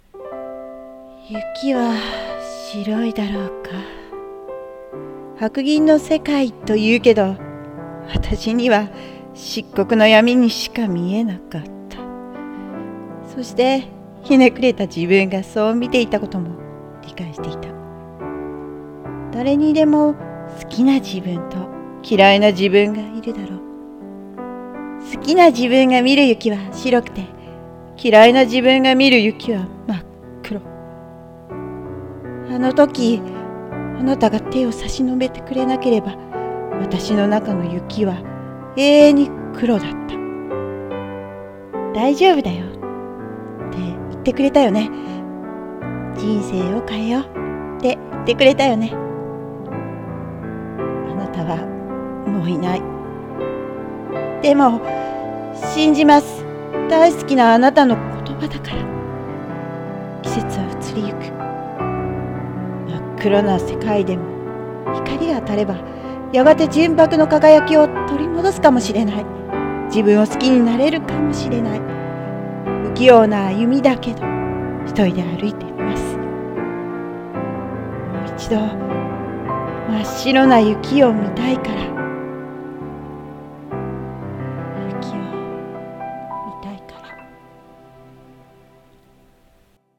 1人用声劇台本「黒い雪」